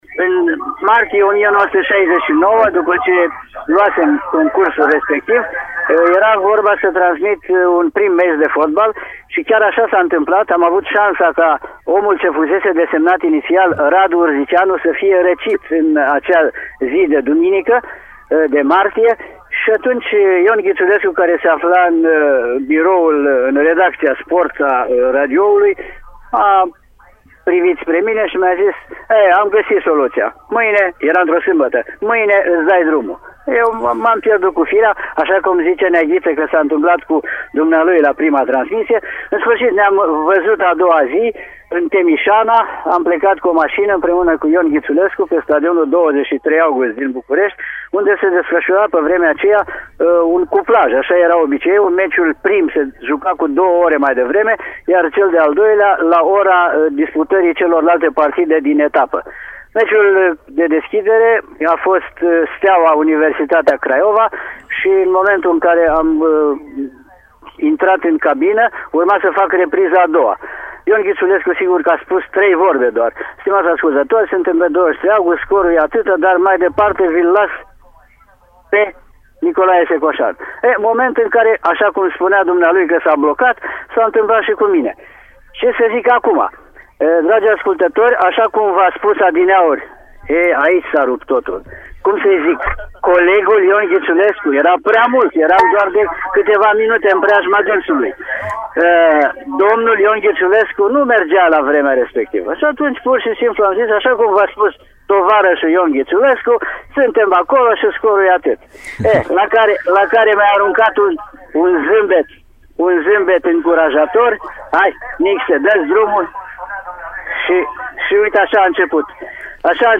La o ediție a Arenei Radio dedicată amintirilor emisiunii „Fotbal Minut cu Minut”, Nicolae Secoșan a povestit momentul debutului său: